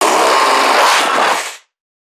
NPC_Creatures_Vocalisations_Infected [7].wav